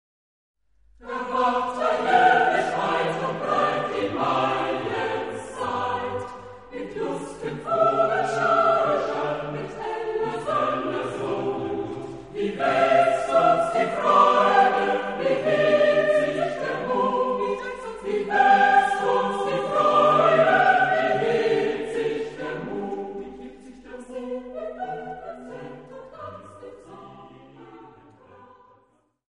Epoque: 19th century
Genre-Style-Form: Secular ; Romantic
Type of Choir: SATB  (4 mixed voices )
Tonality: E minor